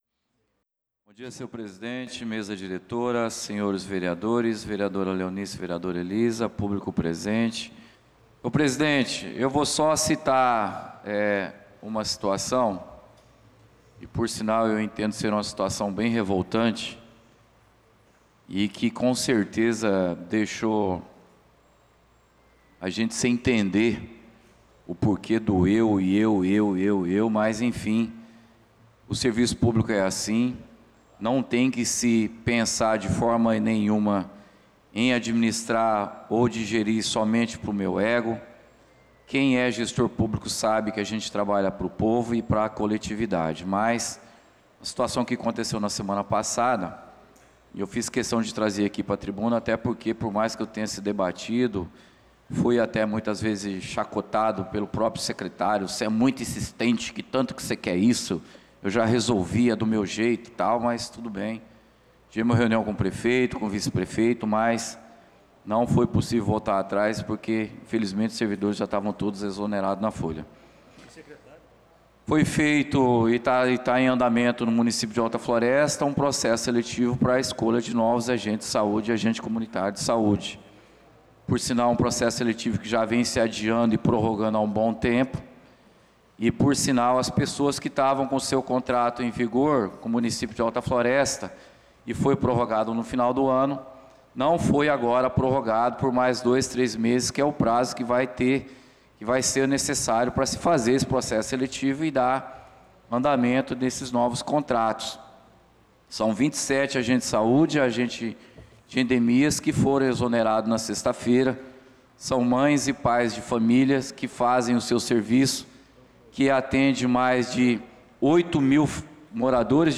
Pronunciamento do vereador Claudinei de Jesus na Sessão Ordinária do dia 05/05/2025